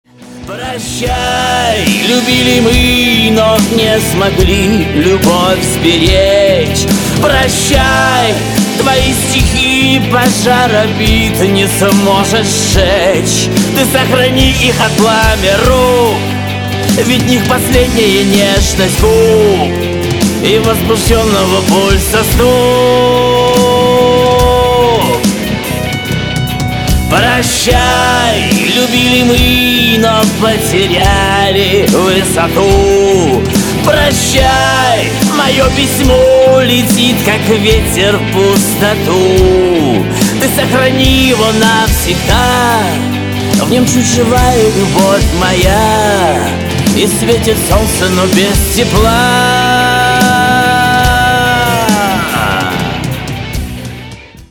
• Качество: 320, Stereo
мужской вокал
грустные
русский шансон
печальные